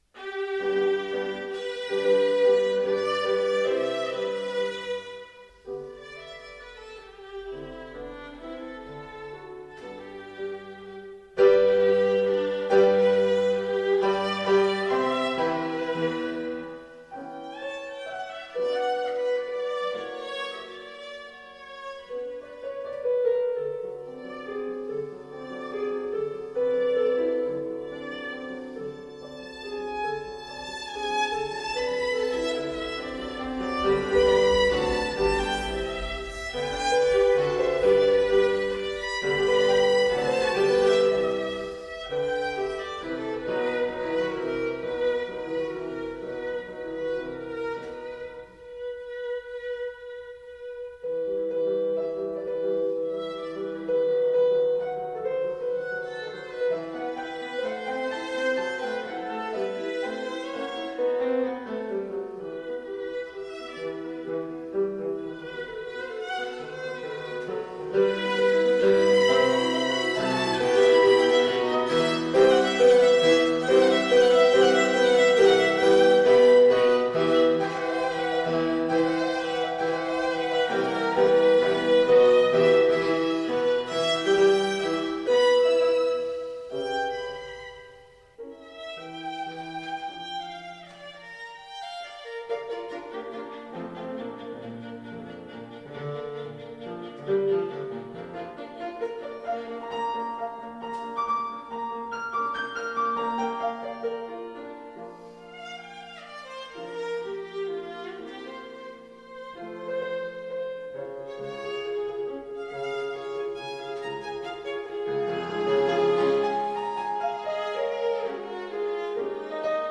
Style: Classical
violin
piano